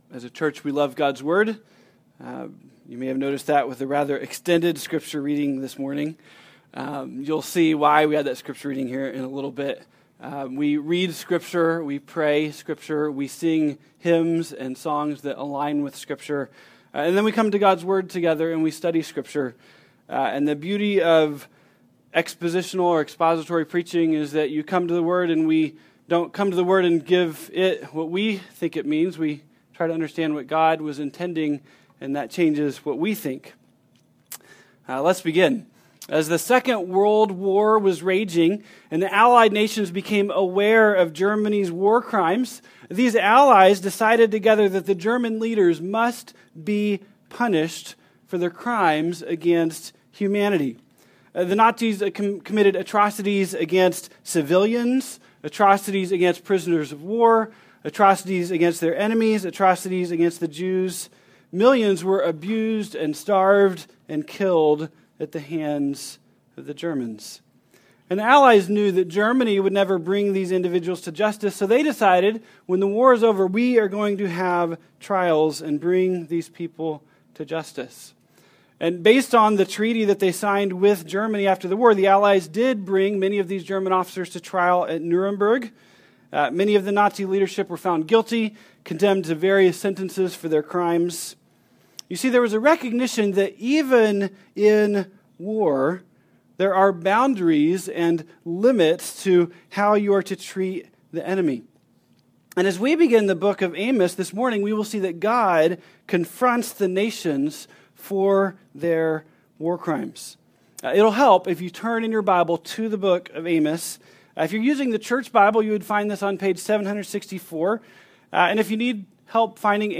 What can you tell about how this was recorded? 2016 ( Sunday AM ) Bible Text